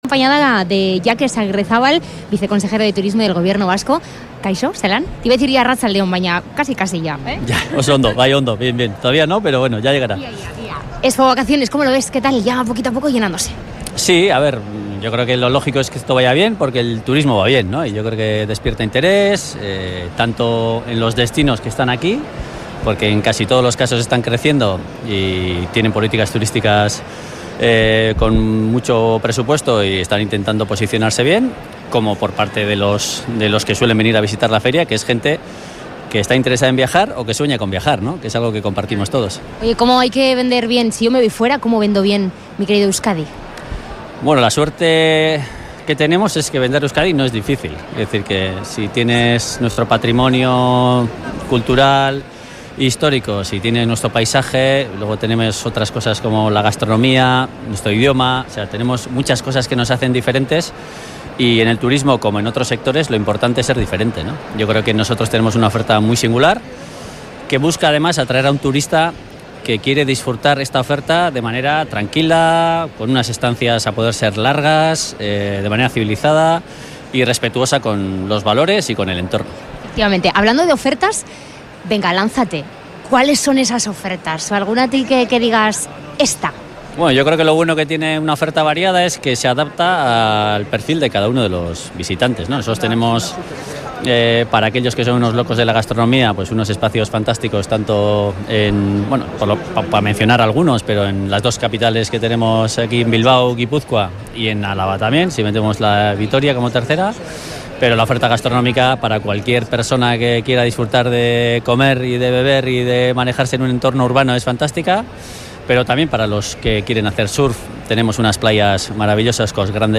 Hablamos con Jakes Aguirrezabal, viceconsejero de Turismo del Gobierno Vasco, en la Feria de Expovacaciones